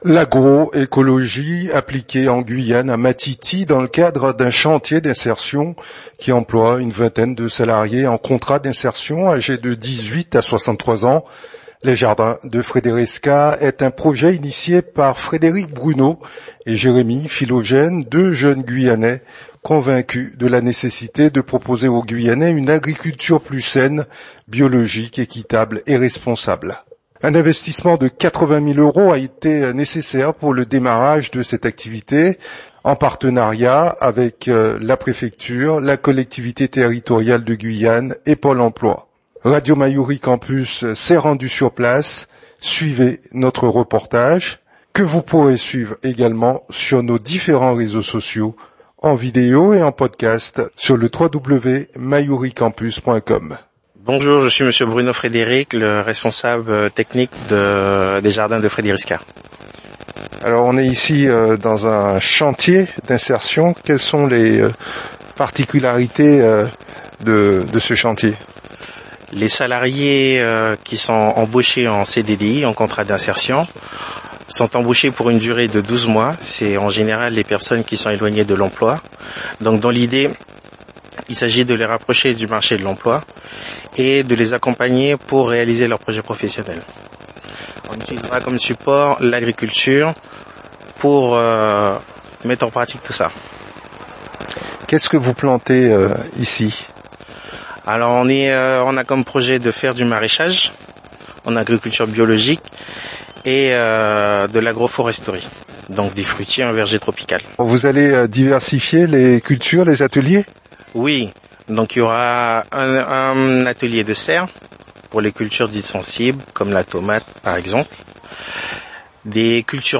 Radio Mayouri Campus était sur place, suivez notre reportage.